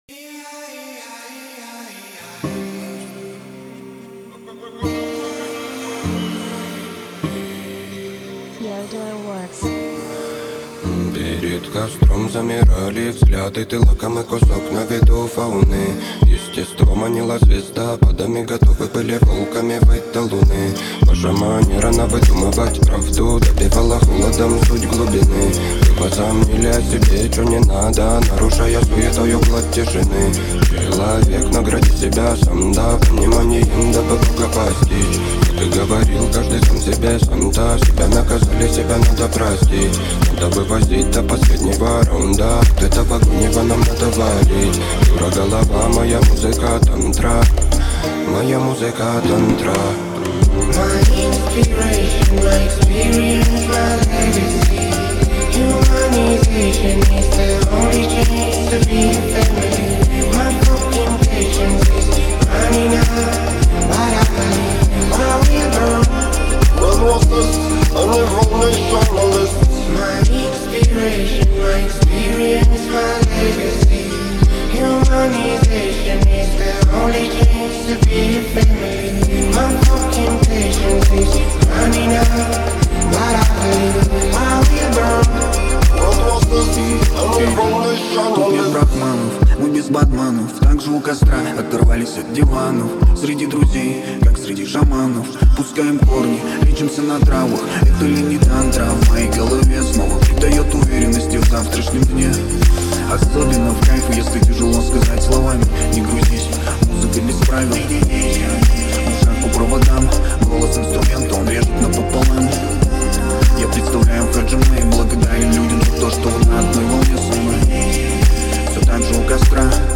мелодичные вокалы
создавая атмосферу загадочности и глубины.